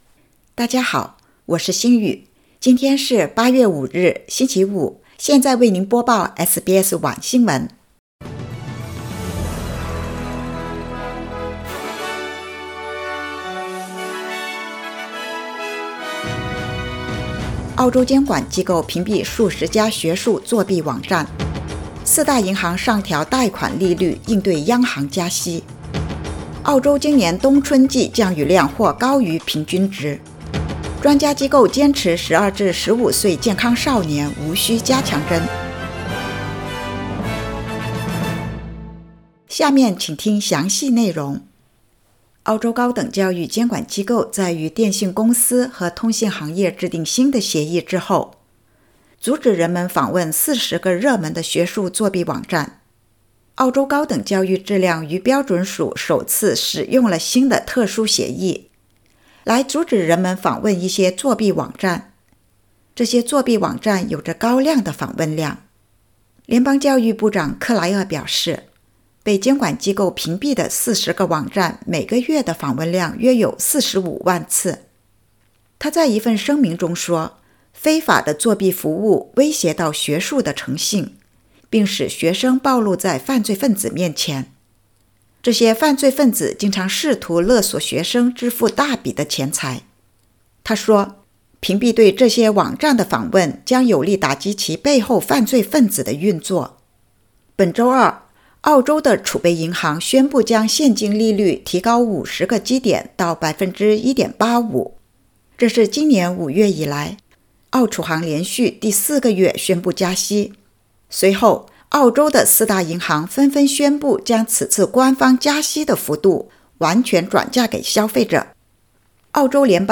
SBS晚新闻（2022年8月5日）